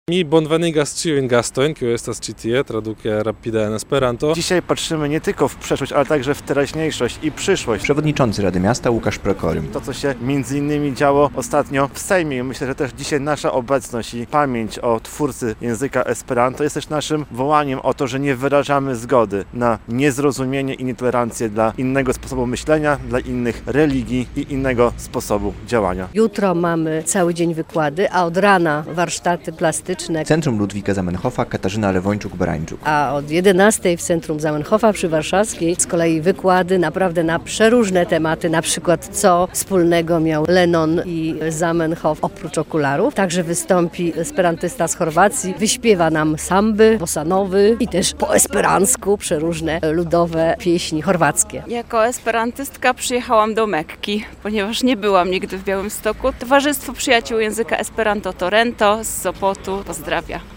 Białostockie Dni Zamenhofa - relacja